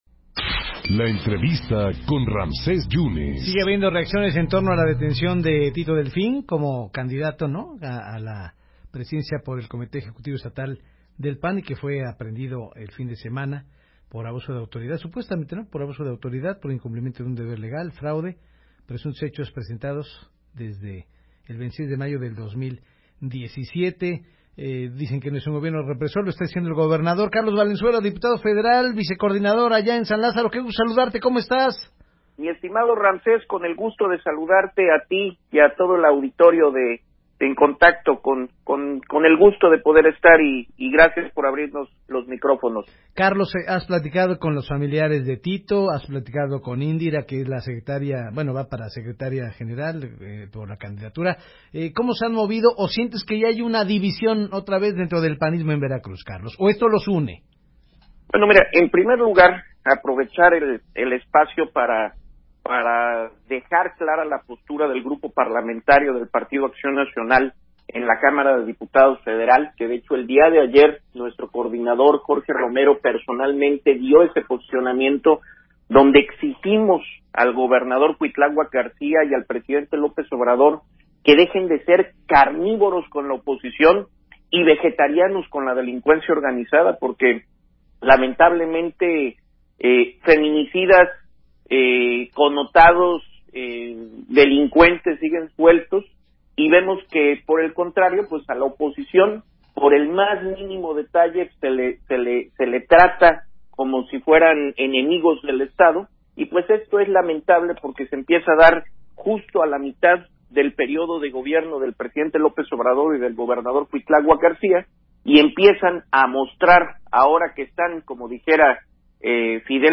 Redacción/Xalapa.- El diputado federal por el PAN, Carlos Valenzuela, platicó para En Contacto sobre la supuesta persecución política que sufrió el candidato a la presidencia del blanquiazul estatal Tito Delfín en Veracruz.